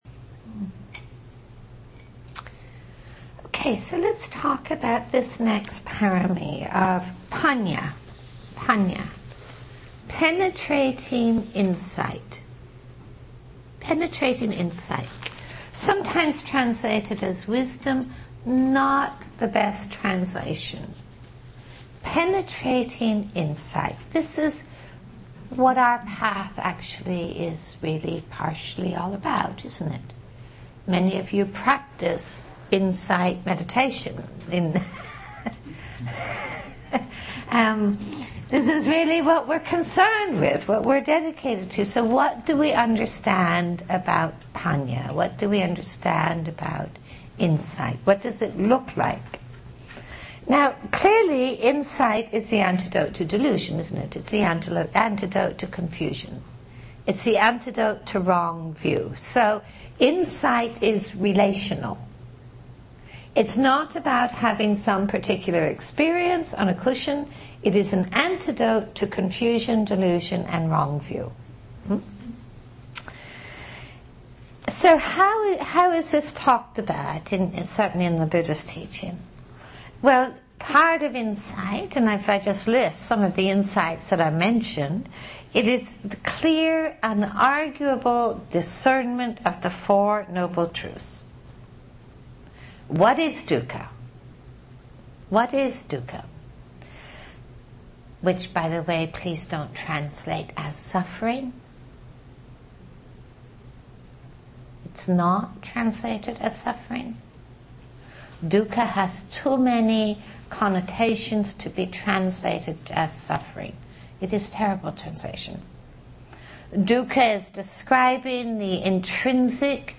Venue: Seattle Insight Meditation Center